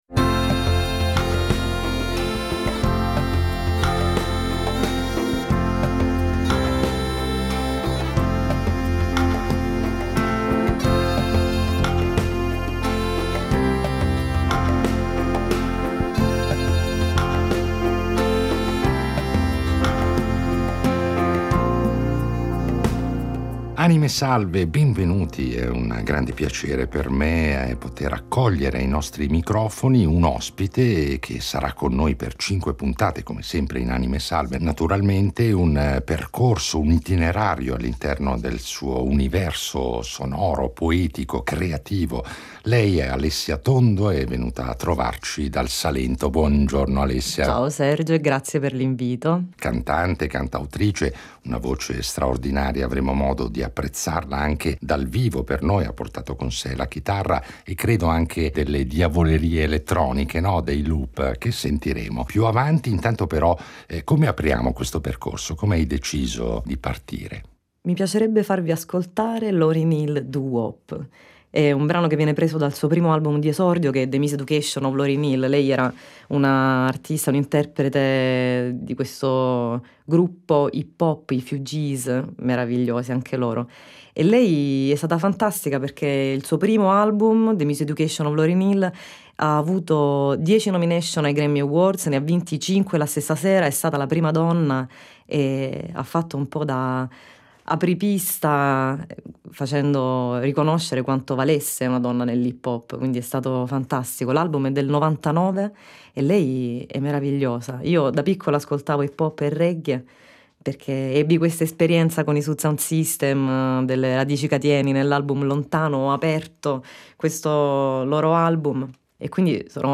È ormai imminente l’uscita del suo primo album da solista, del quale ascolteremo senz’altro alcuni esempi con esecuzioni esclusive, realizzate solo per noi.